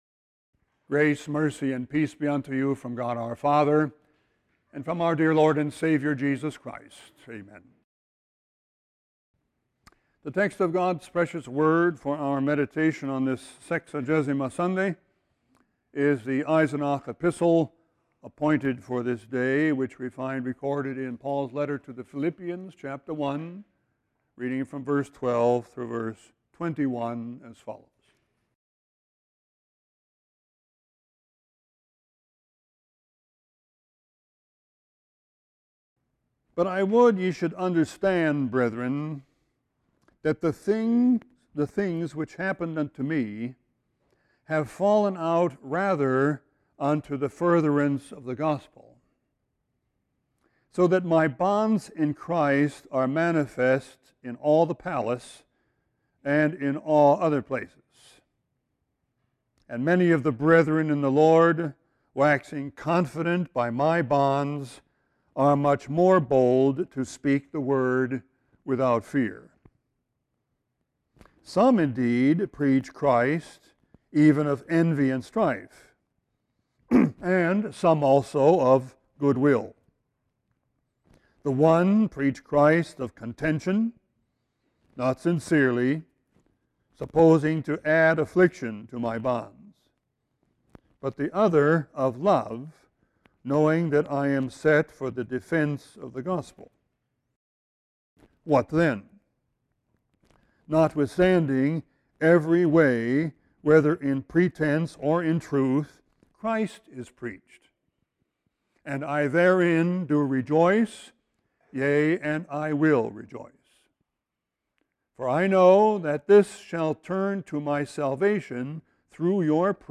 Sermon 1-31-16.mp3